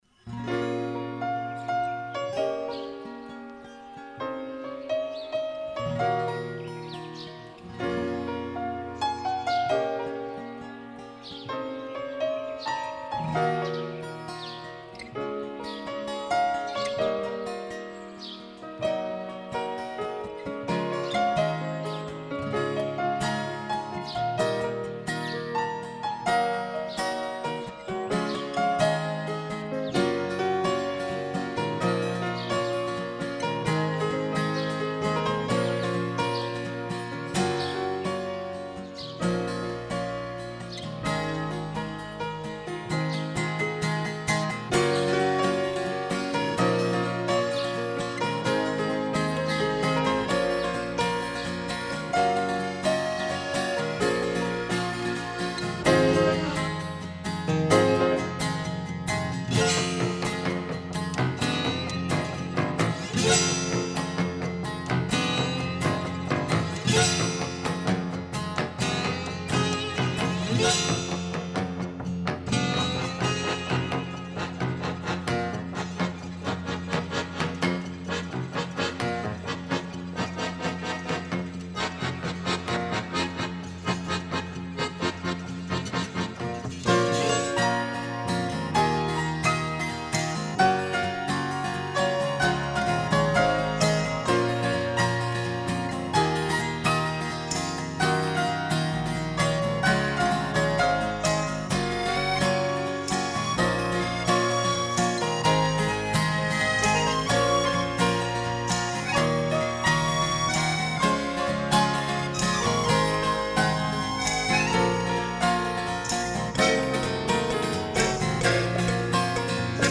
Akk.